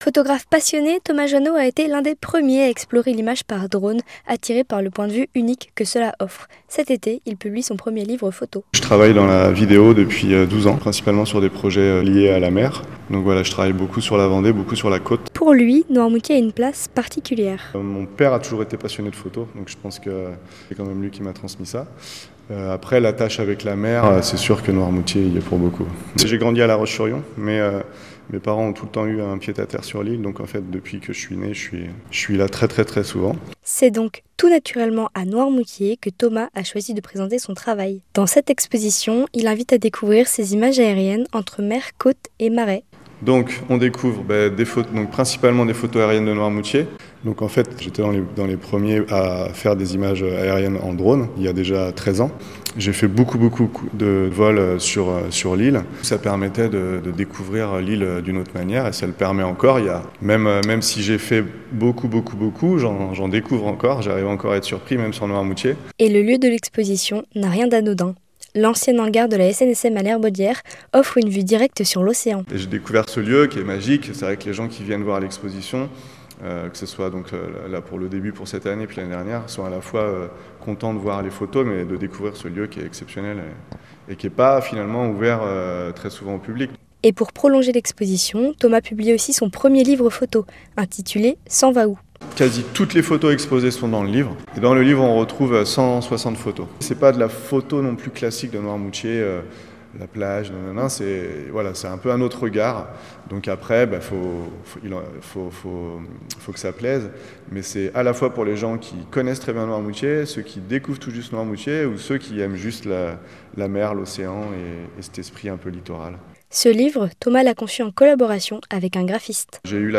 Podcast reportages